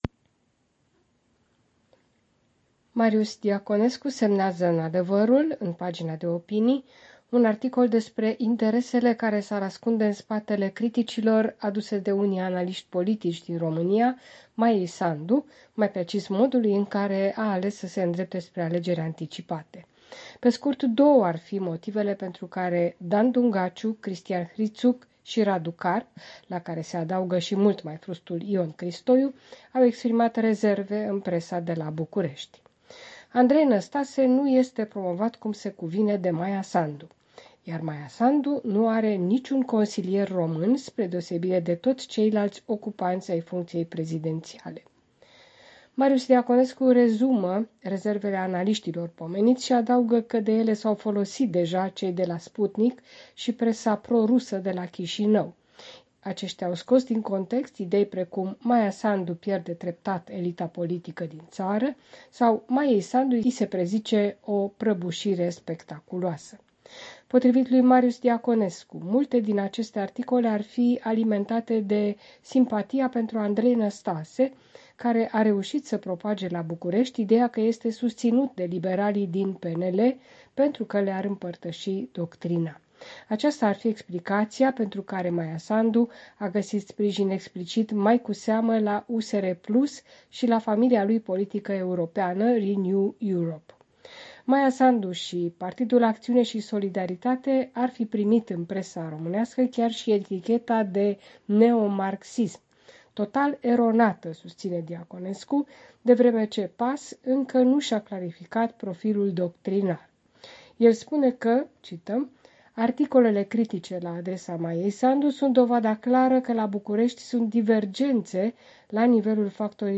Revista presei de la București.